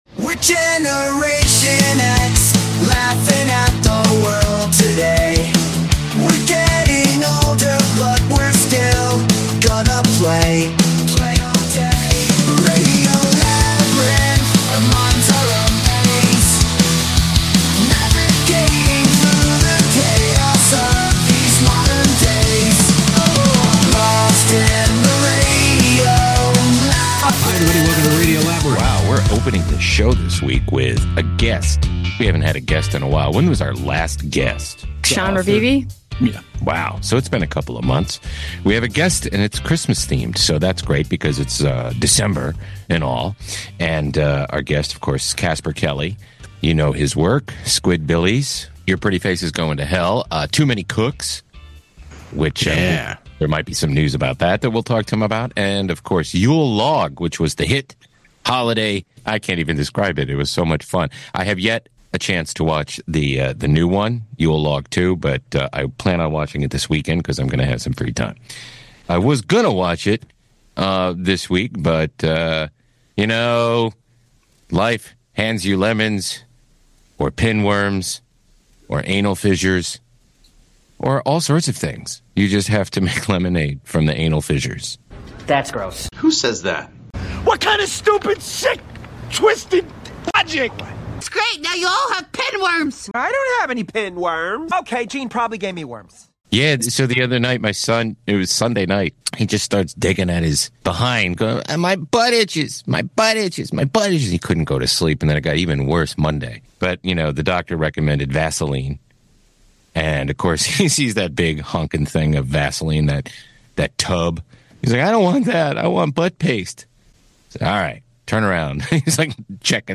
This week, we’re kicking things off with a special guest! Casper Kelly, the brilliant mind behind Adult Swim’s weird and wonderful hits, joins us to chat about Yule Log 2: Branching Out, now streaming on Max.